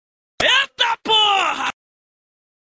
EITA PORRA FUNK - Звуковая кнопка